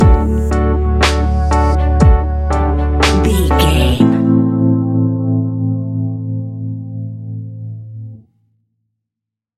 Ionian/Major
laid back
sparse
new age
chilled electronica
ambient
morphing